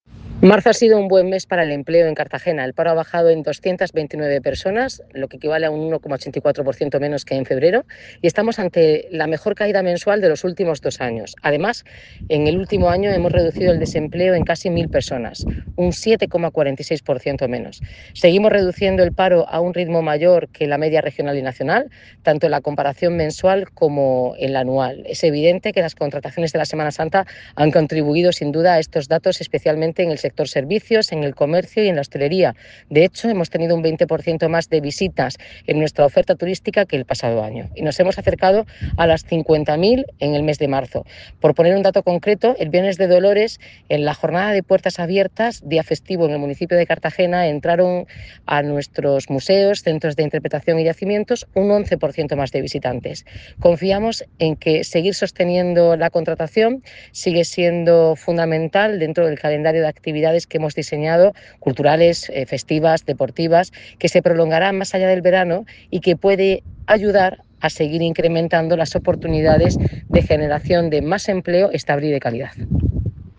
Enlace a Declaraciones de la alcaldesa, Noelia Arroyo, sobre datos del paro en Cartagena